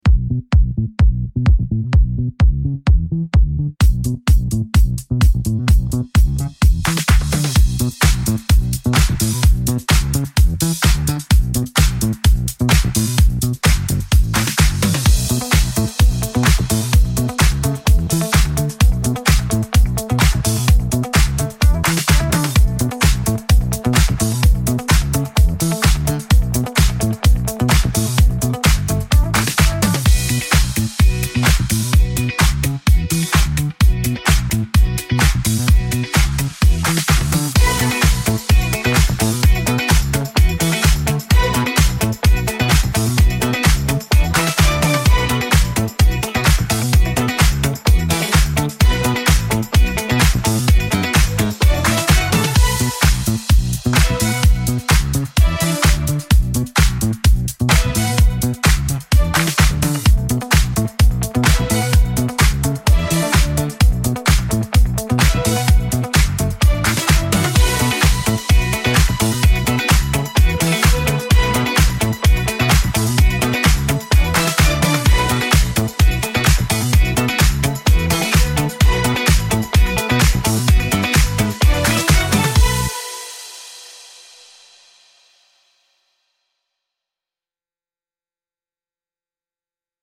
vibrant disco-inspired party music with groovy bassline and sparkly synths